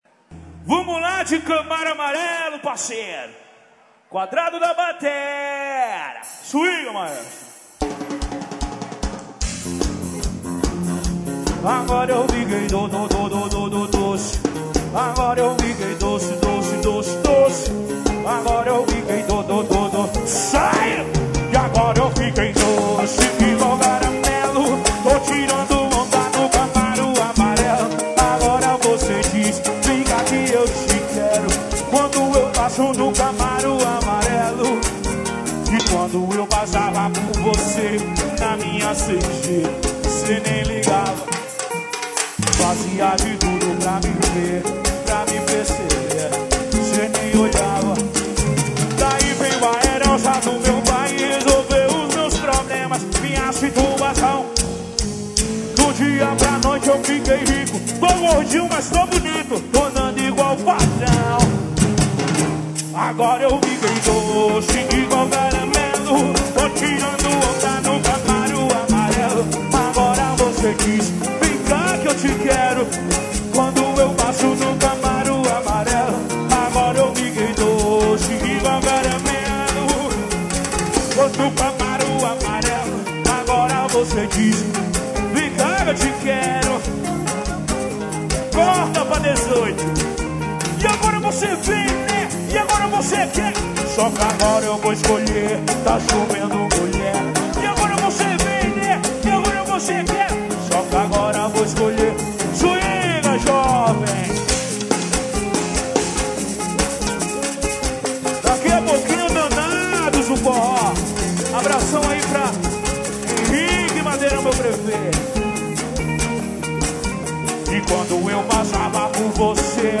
Sertanejo.